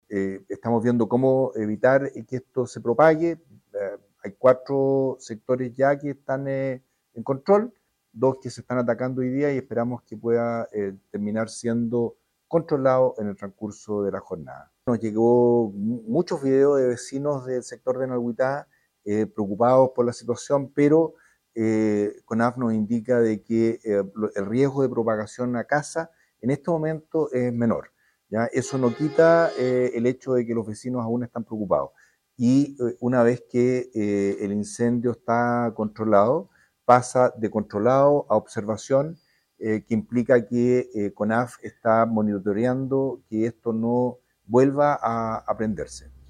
El delegado provincial Marcelo Malagueño sostuvo que los incendios hasta ahora no han afectado viviendas y personas pero no debe esperar que ocurra un siniestro, sino que se deben evitar a toda costa.